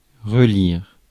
Prononciation
Prononciation France: IPA: [ʁə.liʁ] Le mot recherché trouvé avec ces langues de source: français Traduction Verbes 1.